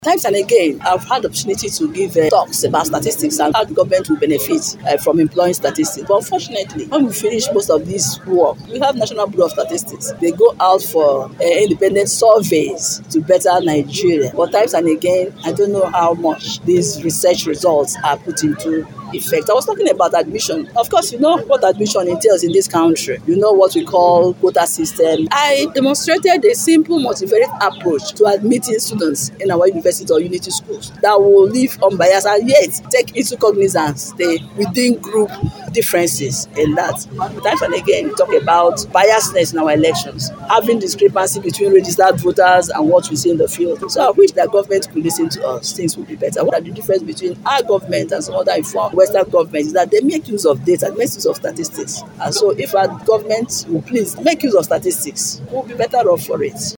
Speaking to newsmen after the event, she maintained that paying serious attention to statistics and the potentials it holds out will enable the Nigerian government in better decision-making and ensure accountability in governance area such as the quota system and the country’s electoral process.